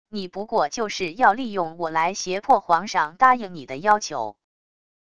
你不过就是要利用我来胁迫皇上答应你的要求wav音频生成系统WAV Audio Player